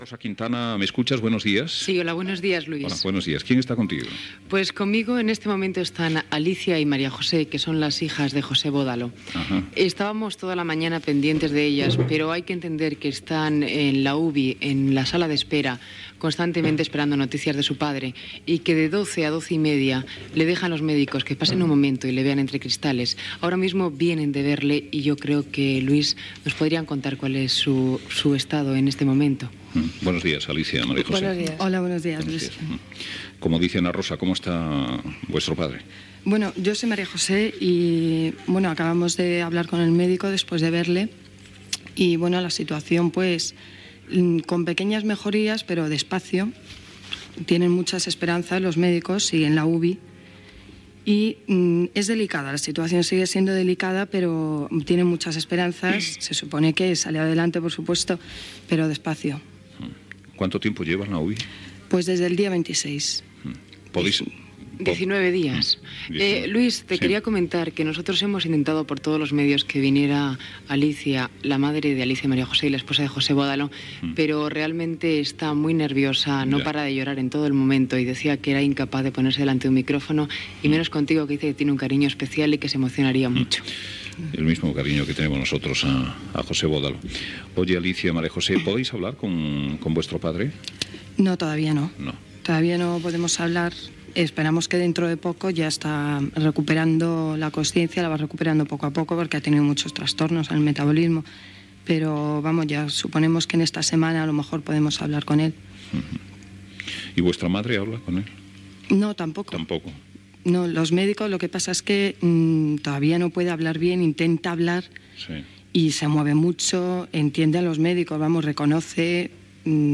Connexió amb la Clínica Rúber de Madrid on està ingressat l'actor José Bódalo, una de les seves filles explica l'estat de salut del seu pare
Info-entreteniment